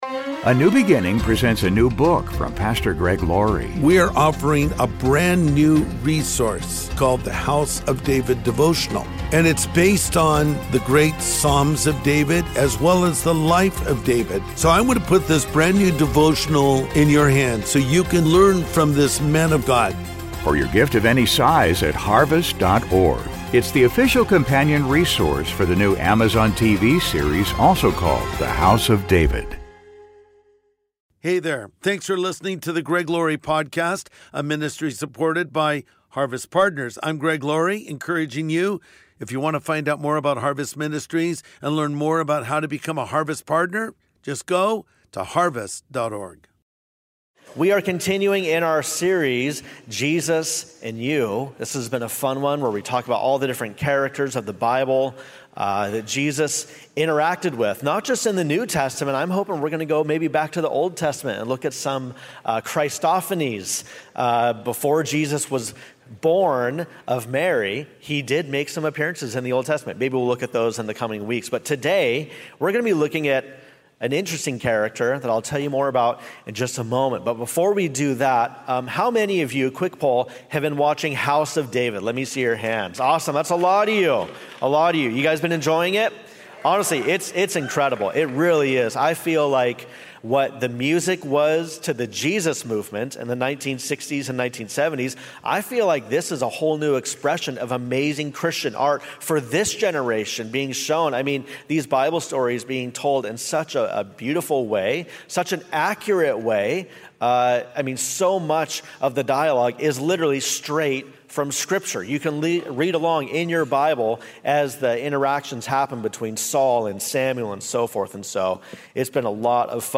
Jesus and the Coward | Sunday Message